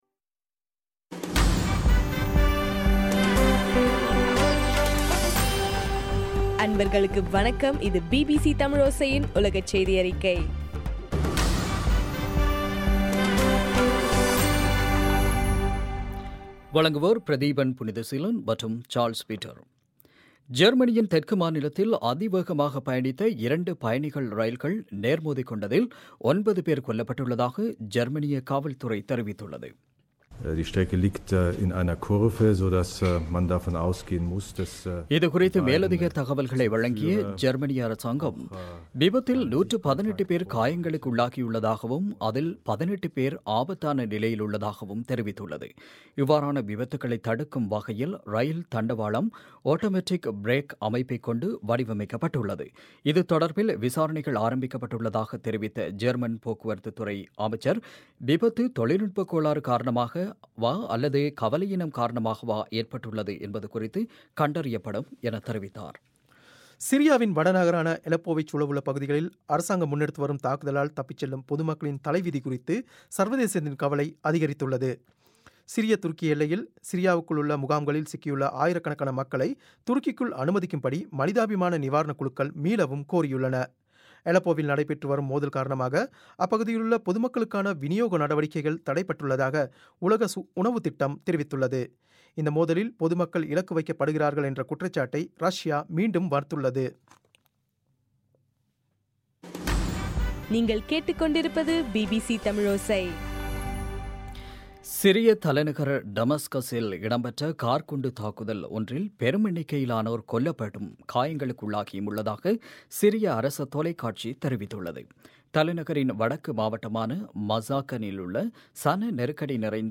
பிப்ரவரி 9 பிபிசியின் உலகச் செய்திகள்